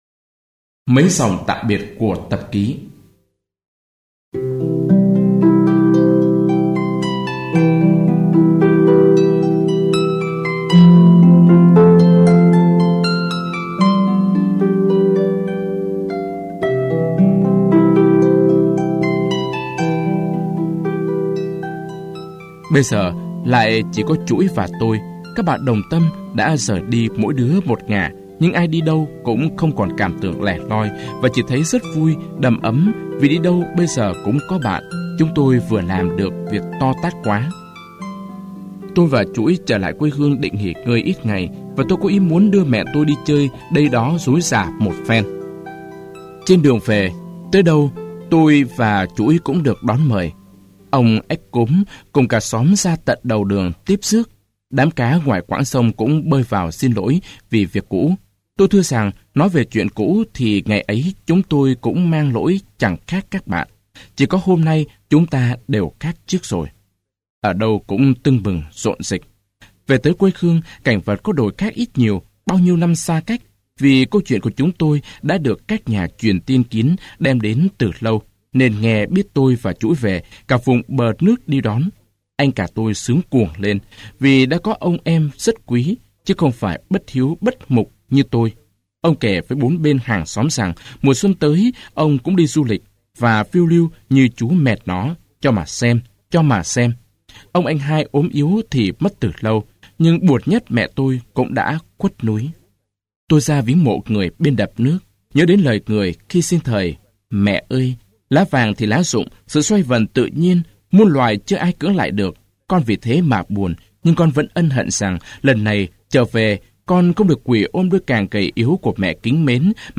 Sách nói | Dế Mèn phiêu lưu ký